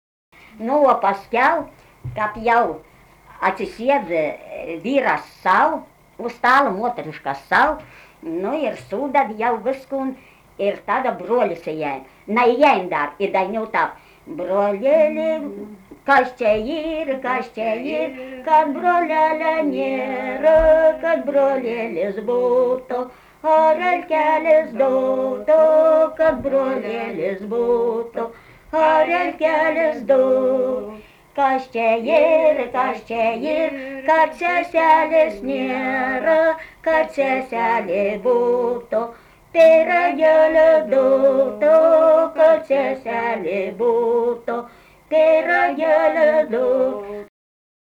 daina, vestuvių
Biržuvėnai
vokalinis